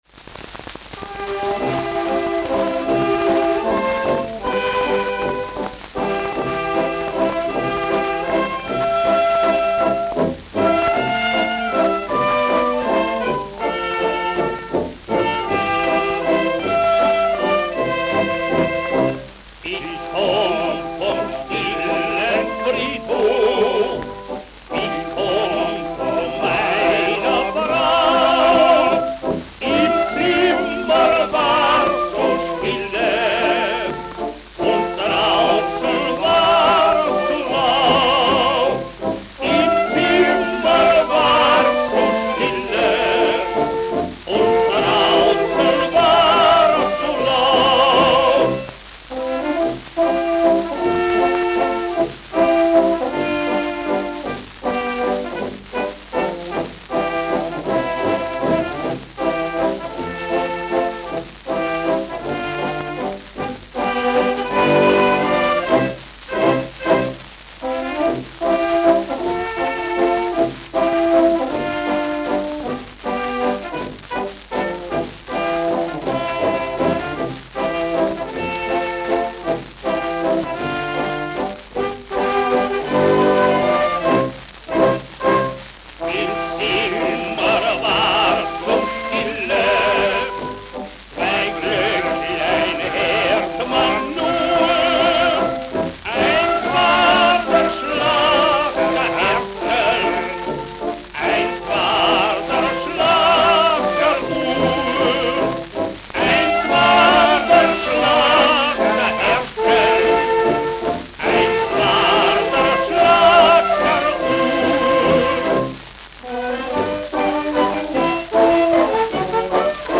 Note: Very worn. Note: Very worn.